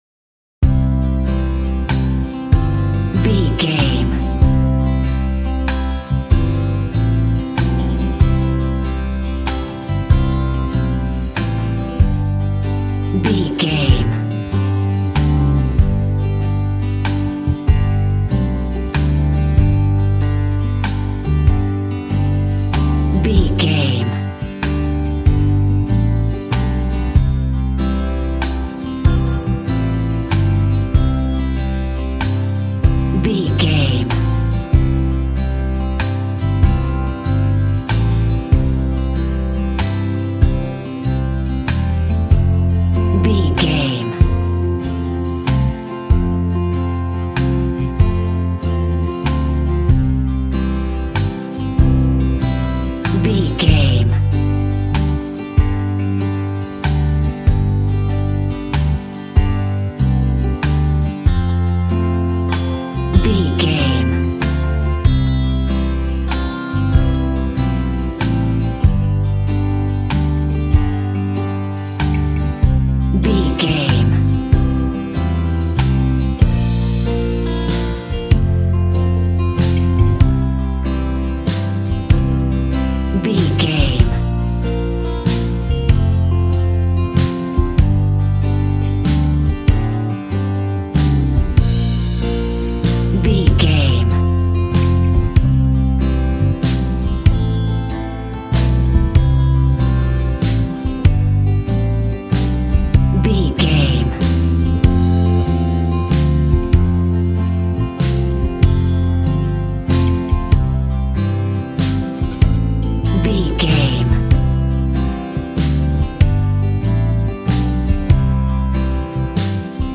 Ionian/Major
cheerful/happy
double bass
drums
piano
50s